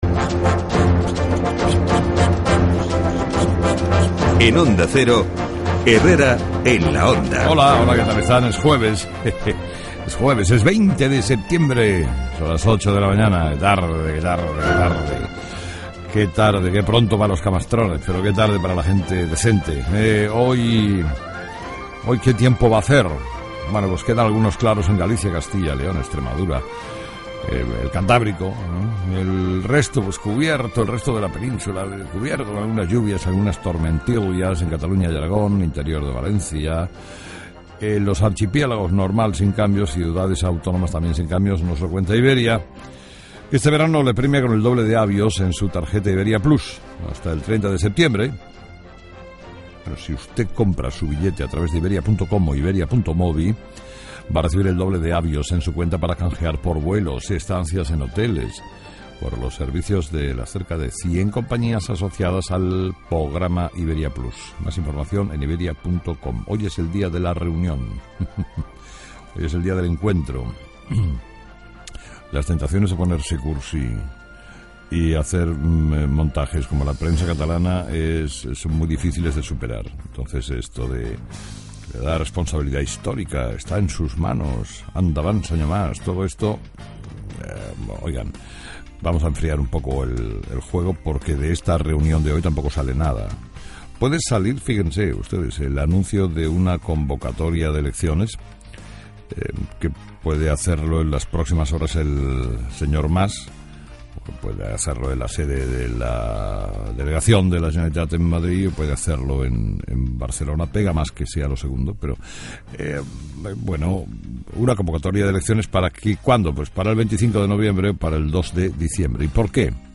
20/09/2012 Editorial de Herrera: 'El día del encuentro entre Rajoy y Mas'